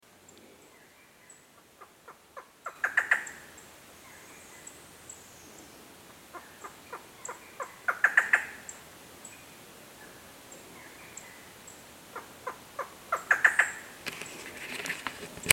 Halcón Montés Chico (Micrastur ruficollis)
DC9A4456_001--halcon-montes-chico.mp3
Nombre en inglés: Barred Forest Falcon
Fase de la vida: Adulto
Localidad o área protegida: Parque Nacional Chaco
Condición: Silvestre
Certeza: Fotografiada, Vocalización Grabada